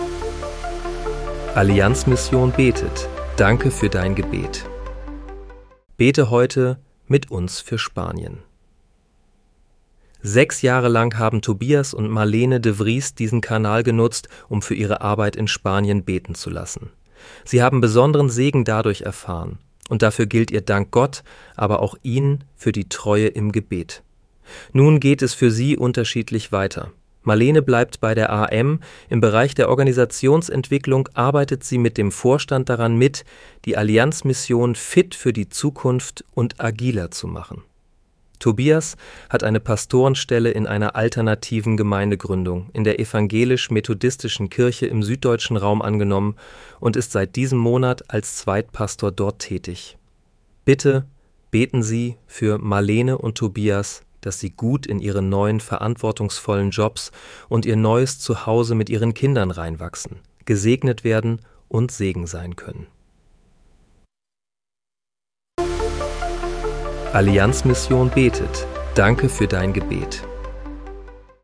Bete am 01. Dezember 2025 mit uns für Spanien. (KI-generiert mit